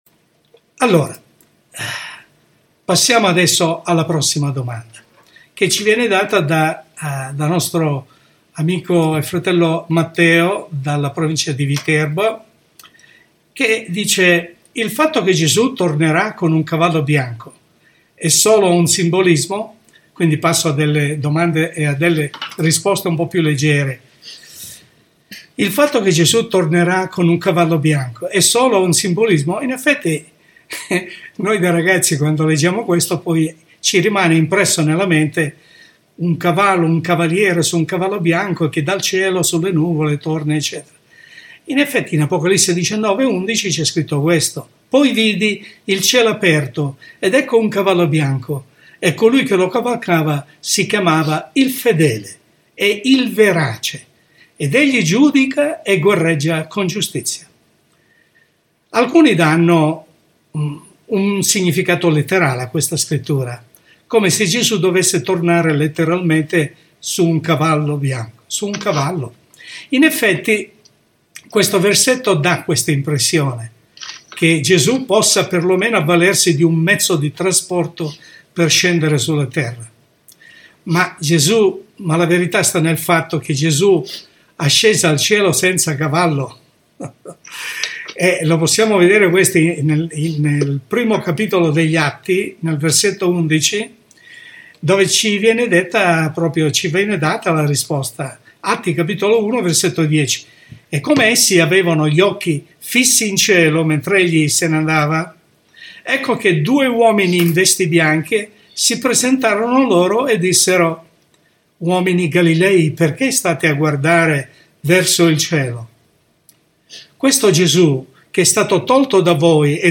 Studio Biblico